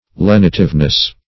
Lenitiveness \Len"i*tive*ness\, n. The quality of being lenitive.